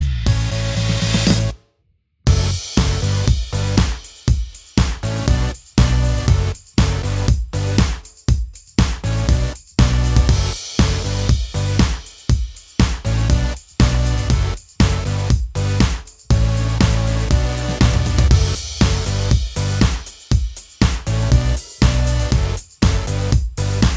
For Guitarists